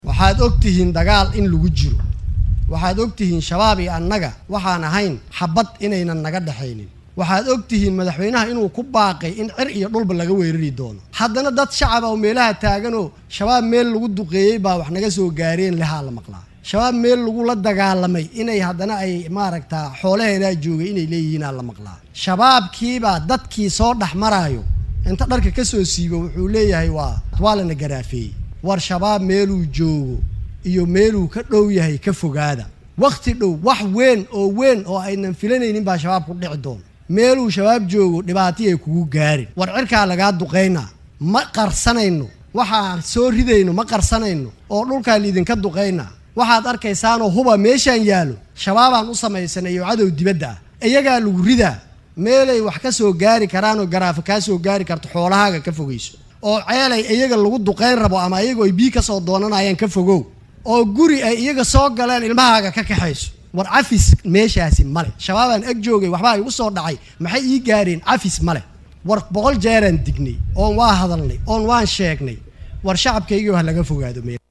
Jaamac oo khudbad ka jeediyay munaasabad xarigga looga jarayay dhismaha cusub ee xarunta Ciidanka Dhulka ee Xoogga Dalka Soomaaliyeed ayaa sheegay in guullo waaweyn laga gaaray dagaalka ka dhanka ah kooxda Al-Shabaab, welina uu sii socdo gulufka ka dhanka ah kooxdaasi.